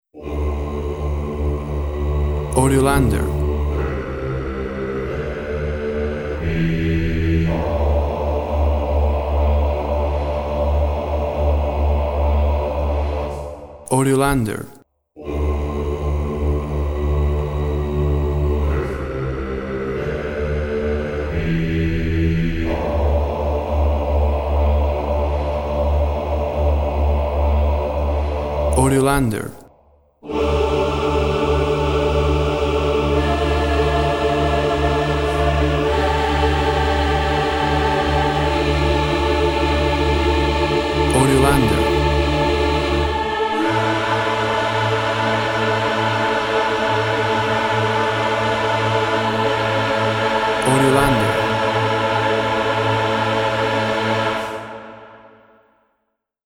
Choir singing with intensity.
Tempo (BPM) 52